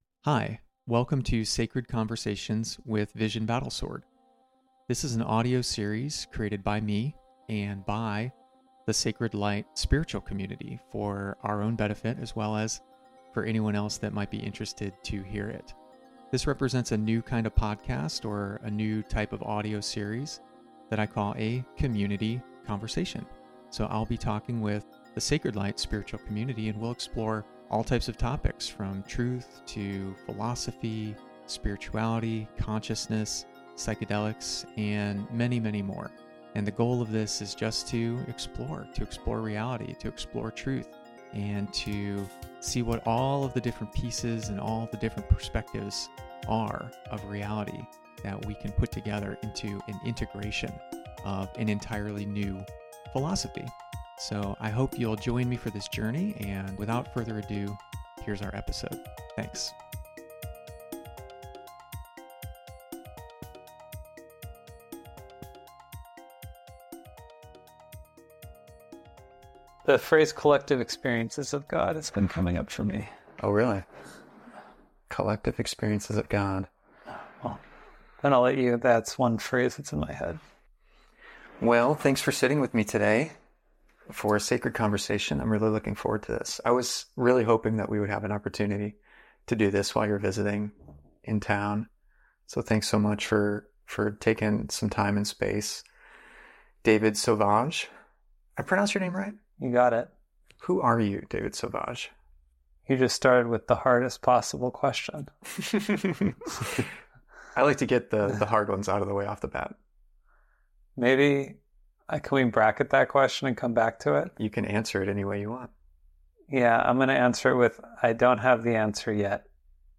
conversation04-money.mp3